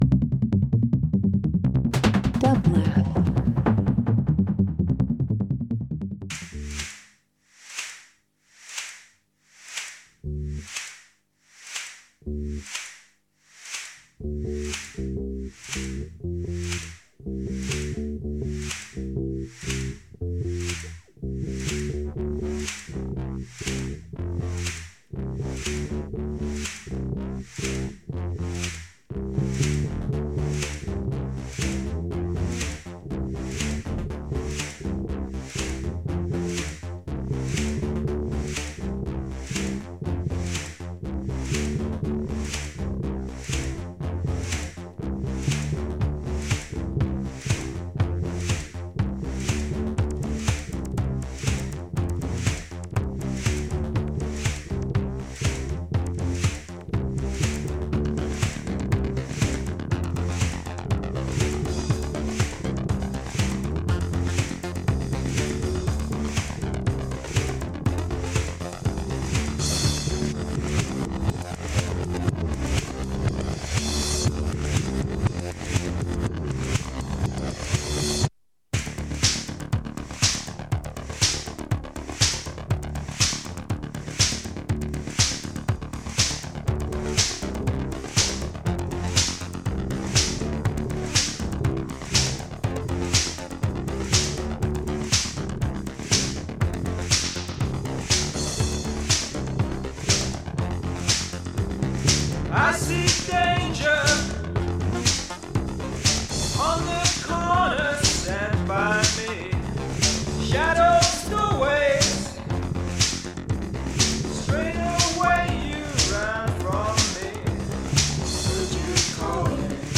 Alternative Electronic Jazz Pop